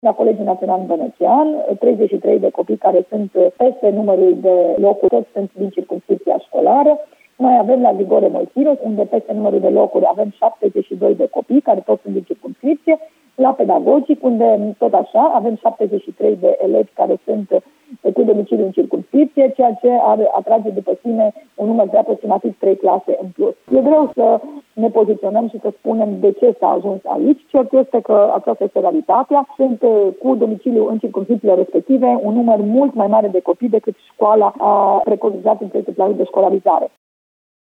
Şef al inspectoratului şcolar din Timiş, Aura Danielescu: